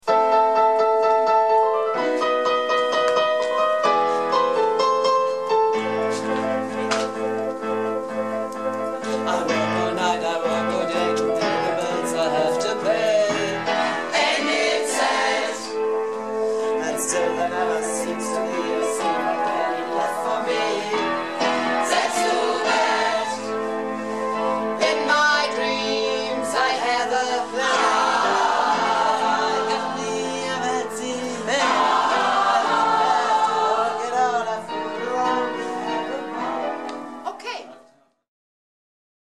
Das ganz gro�e Los - Chorprobe am 11.02.14